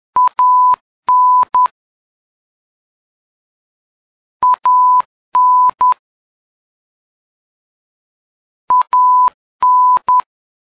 The "AN" message repeats three times in ten seconds and followed by a 15 second pause.
simulated AN signal).
ANbeacon.mp3